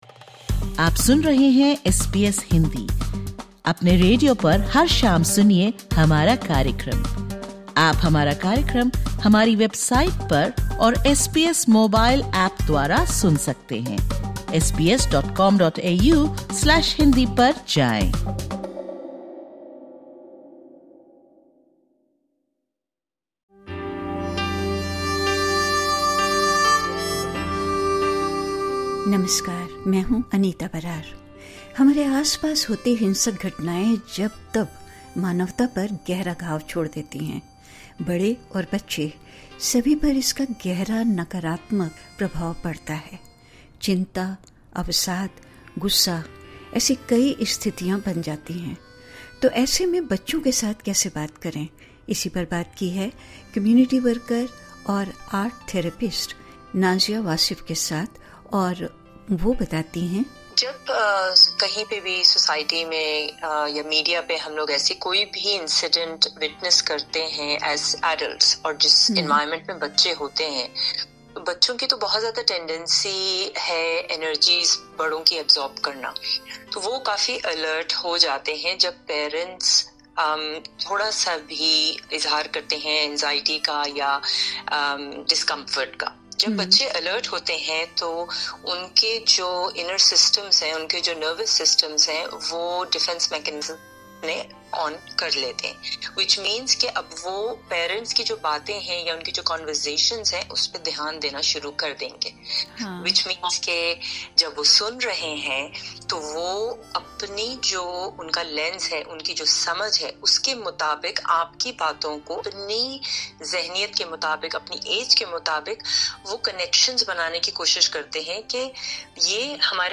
Disclaimer: The advice expressed in this interview is of a general nature.